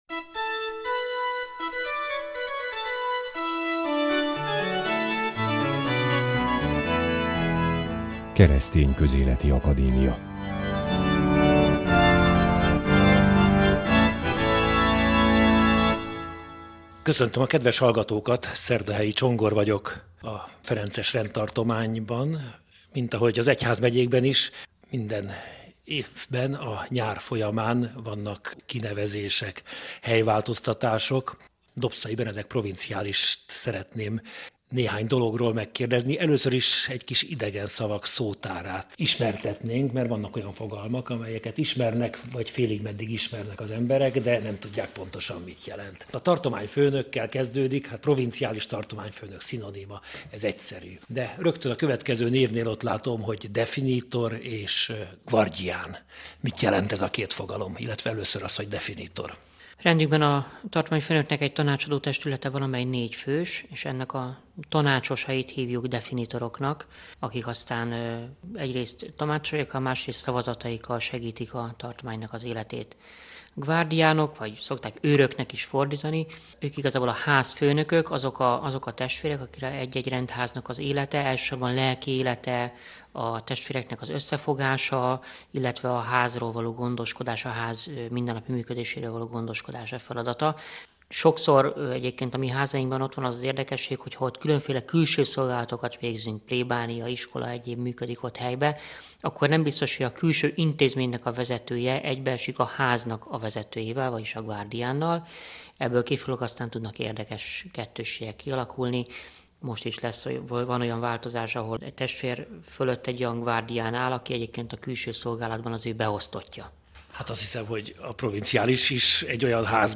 A műsor itt meghallgatható.